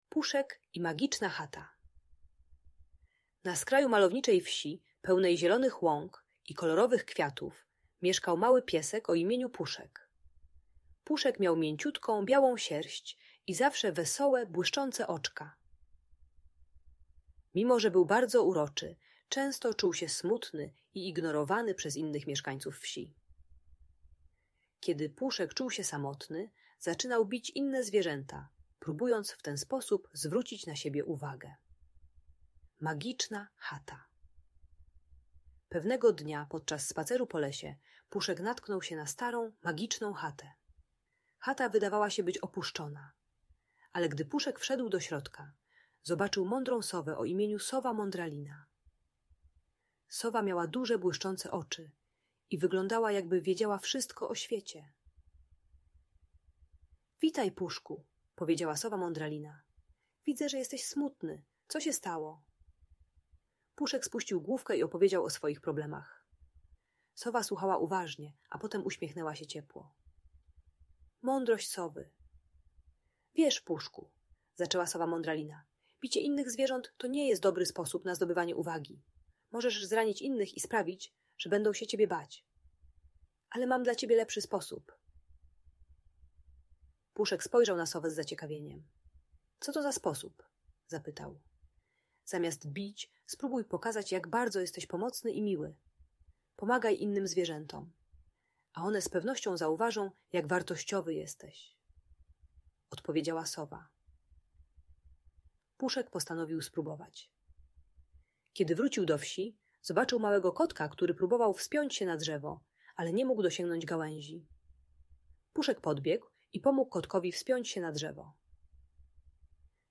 Puszek i Magiczna Chata - Urocza historia o Przyjaźni - Audiobajka